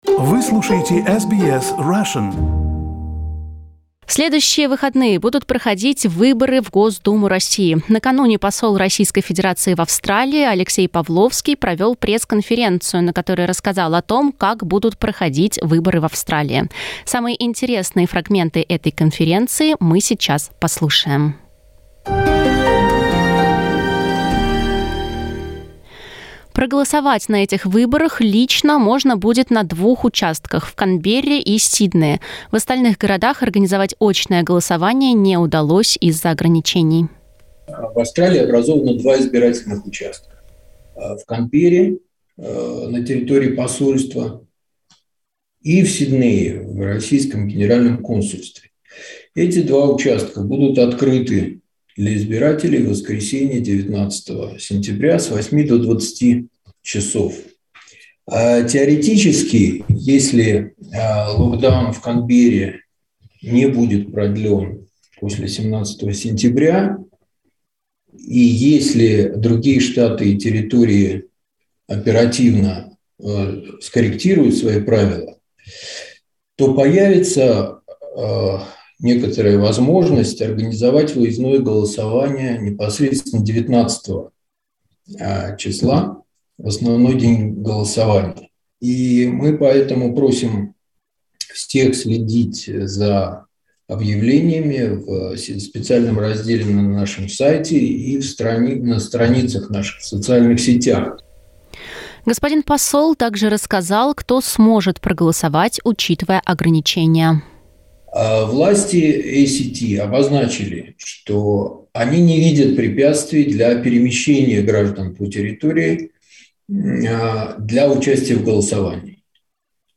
В следующие выходные будут проходить выборы в Госдуму России. Посол РФ в Австралии Алексей Павловский провел пресс-конференцию, на которой рассказал о голосовании в Австралии.